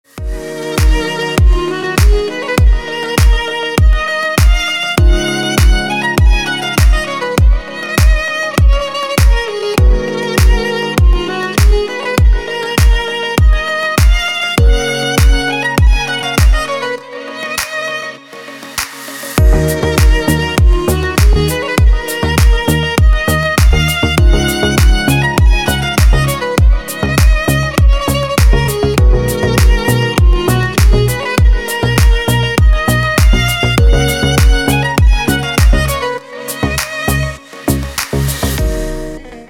• Качество: Хорошее
• Песня: Рингтон, нарезка